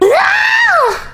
Worms speechbanks
Kamikaze.wav